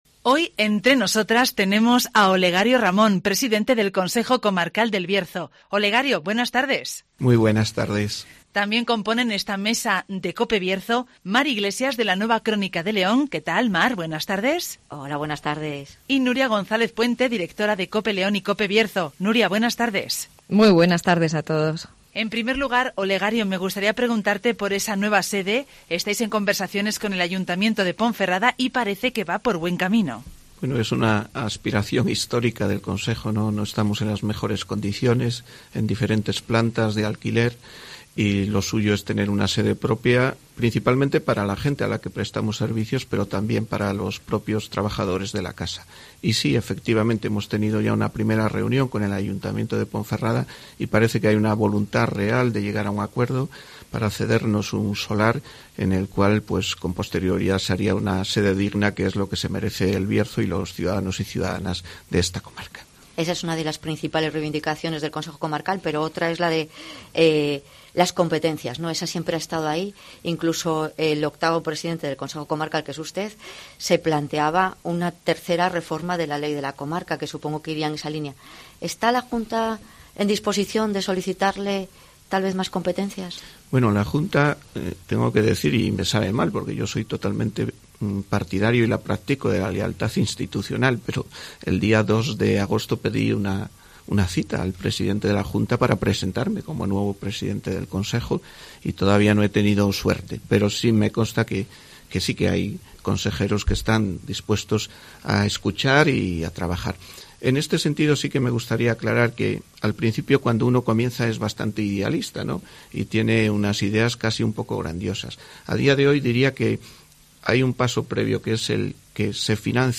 Olegario Ramón, presidente del Consejo Comarcal del Bierzo, es el invitado del espacio "Entre Nosotras" que realizan la Cadena Cope y La Nueva Crónica de León.